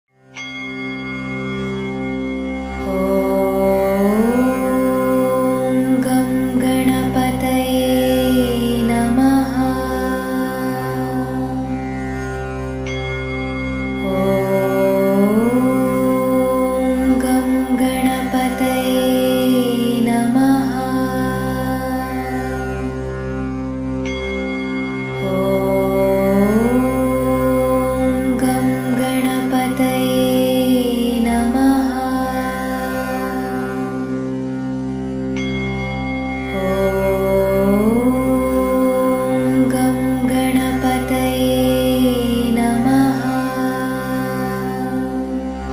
ganesha-mantra.mp3